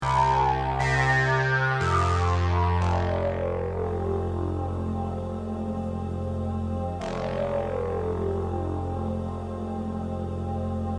Ce synthétiseur est doté d'une synthèse unique à distortion de phase, propre à Casio. Le son est assez similaire au DX7, cependant il est plus simple à programmer.
Sound demo